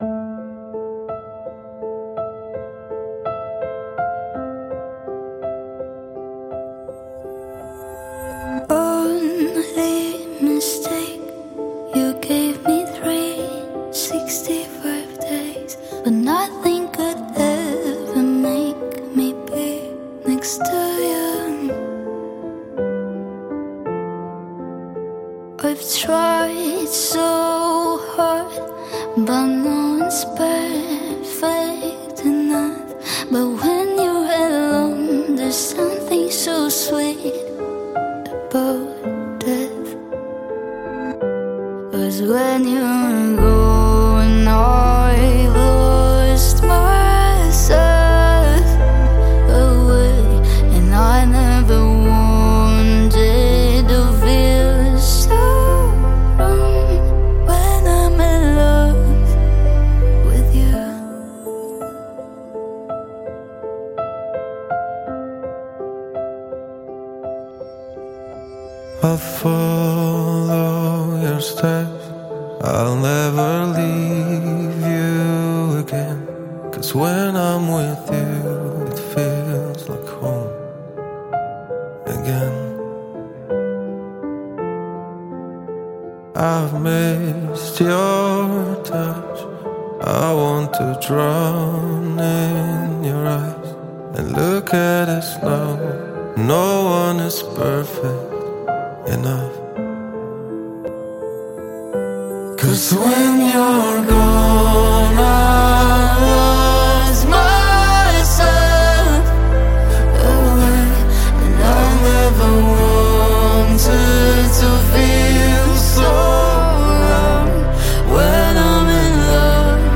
آهنگ خارجی
آهنگ غمگین